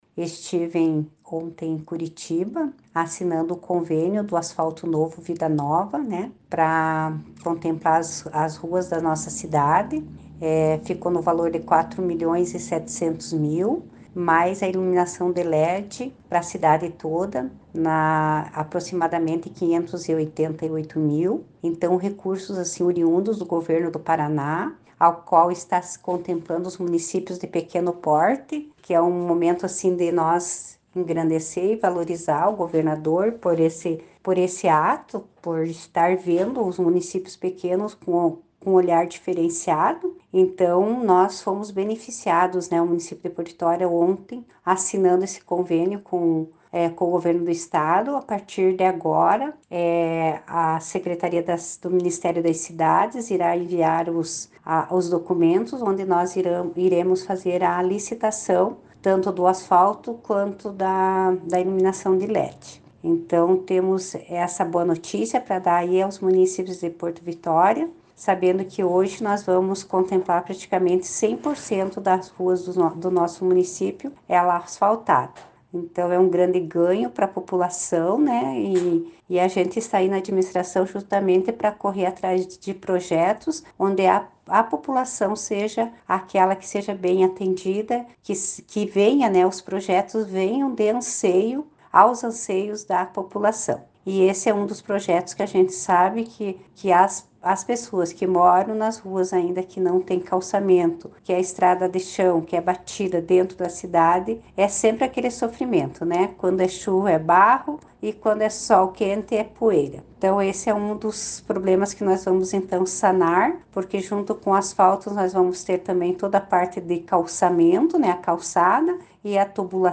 Confira o áudio da prefeita Marisa Ilkiu sobre a liberação de recursos.